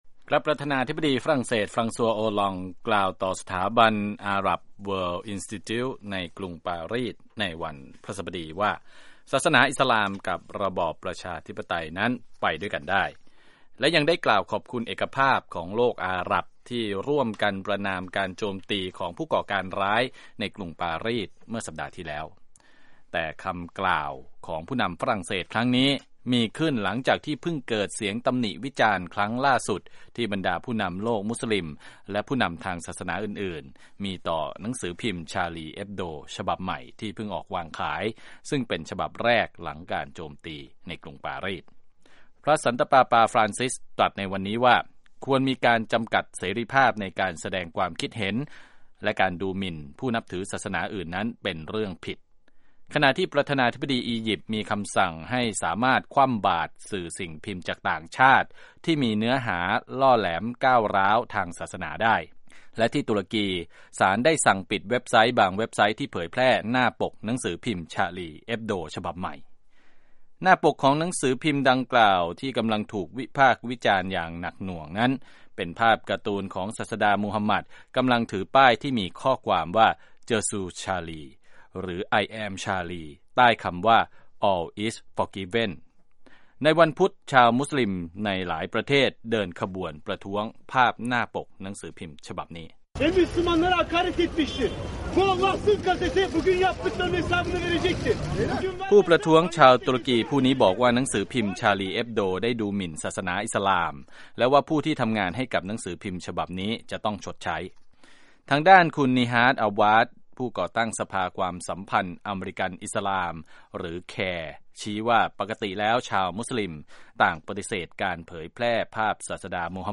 รายงานจากผู้สื่อข่าว